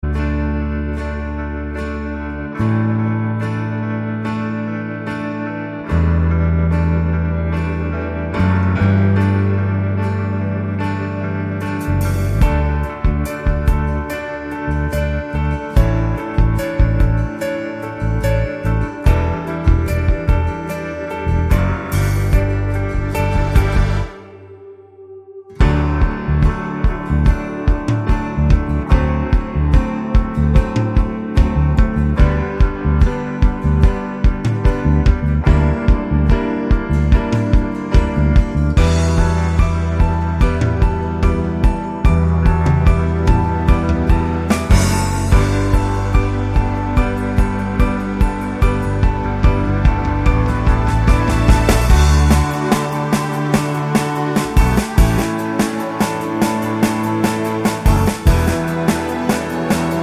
no Backing Vocals Musicals 3:47 Buy £1.50